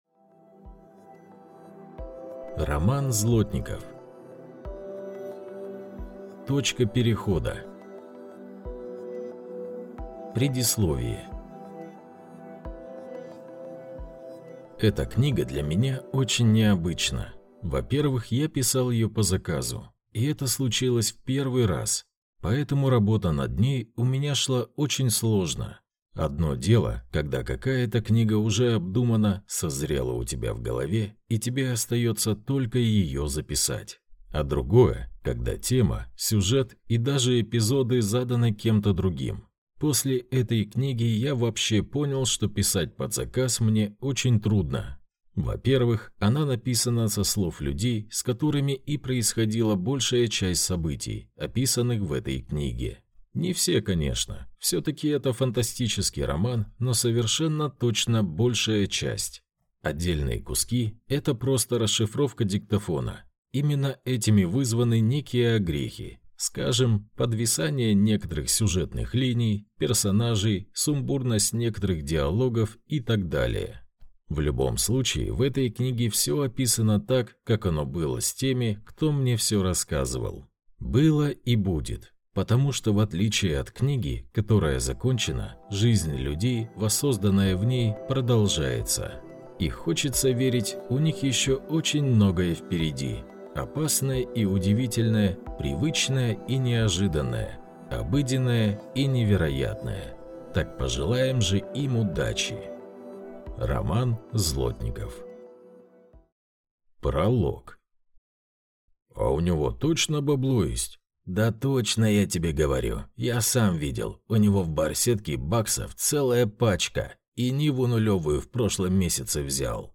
Точка перехода (слушать аудиокнигу бесплатно) - автор Роман Злотников